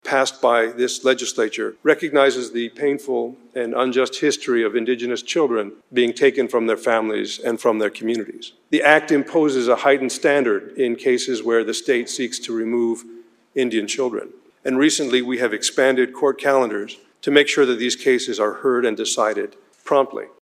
WA Supreme Court Chief Justice González Delivers State of the Judiciary Address (Listen/Watch)